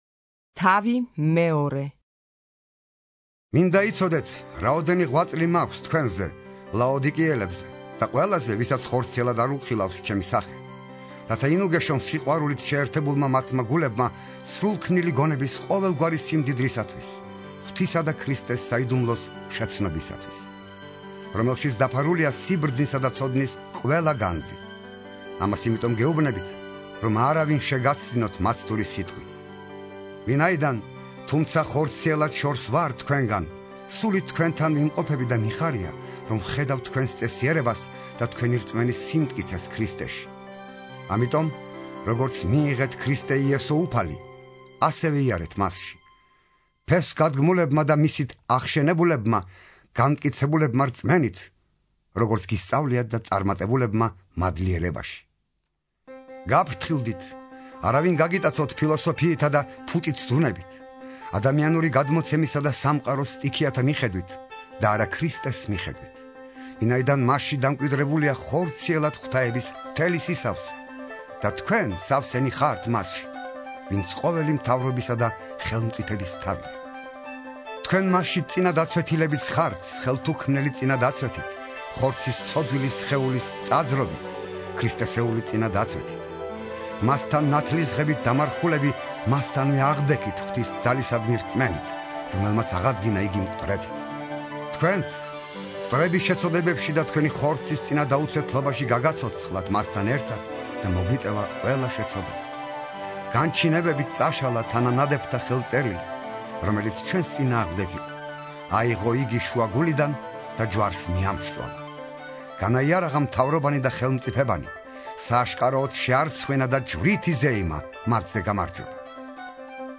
(51) ინსცენირებული ახალი აღთქმა - პავლეს ეპისტოლენი - კოლასელთა მიმართ